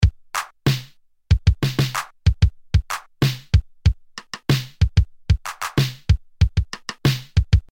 TR-626 Drum machine
A real 1987 TR-626 Drum Machine recorded via a Tube Amp, controlled by Cubase 8.